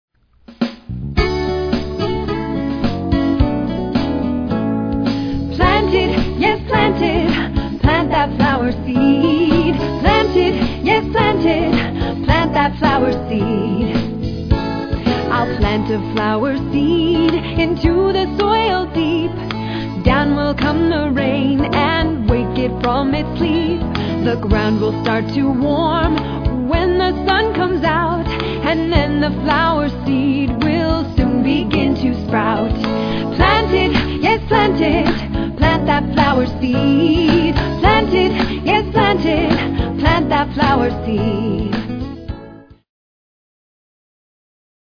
Listen to a sample of this song.